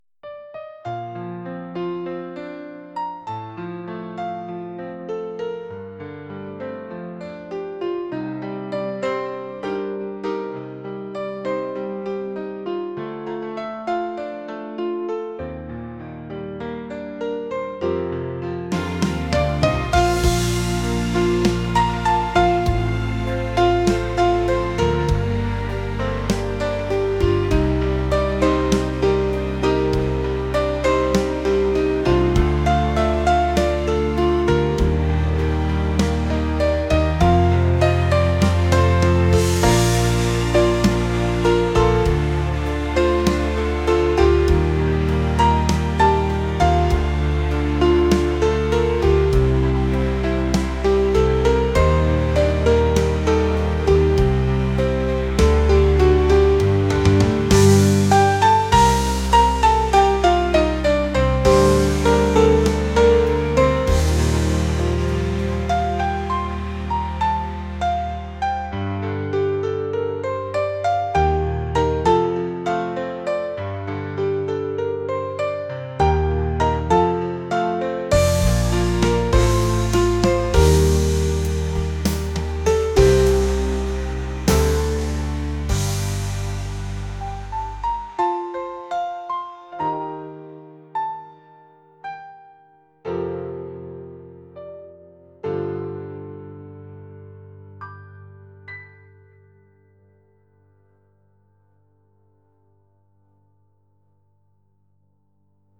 pop | soul & rnb | acoustic